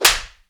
SLAP   3.WAV